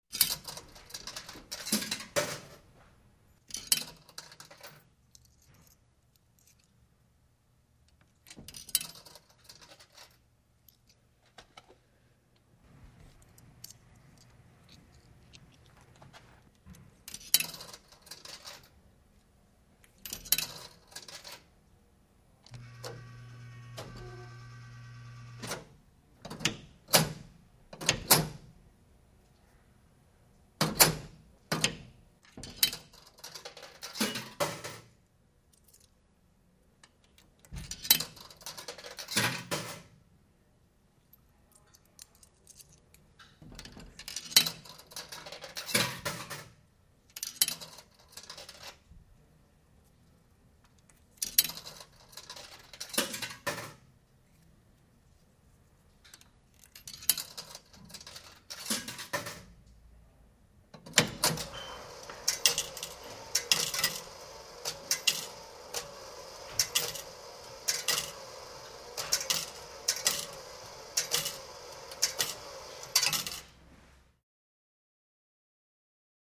Coins Into Vending Machine, W Handle Pull And Mechanical Activity.